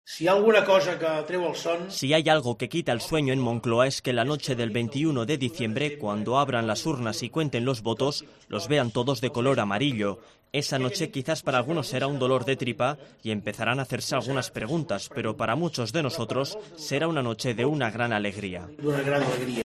A través de videoconferencia desde Bruselas, ha criticado al "tripartito del 155" y sobre todo al candidato del PSC.